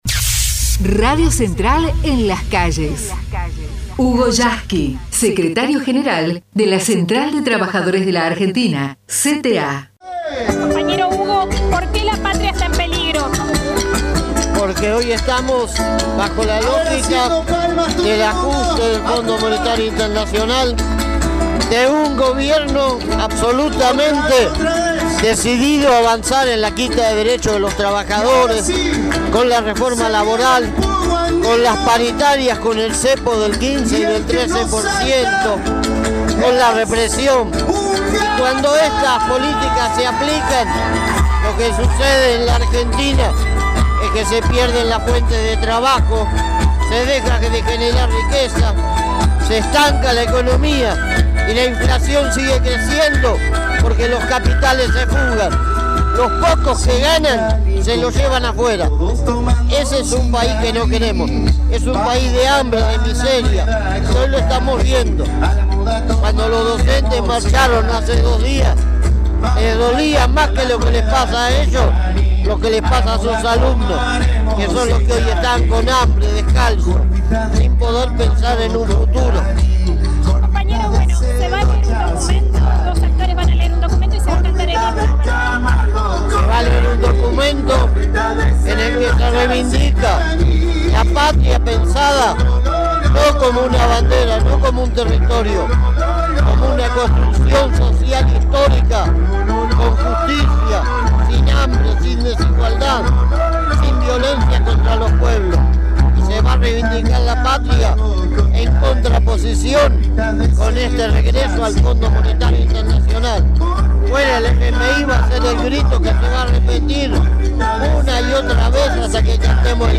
Secretario General de la CTA de los Trabajadores y Diputado Nacional en el acto por el 25 de Mayo.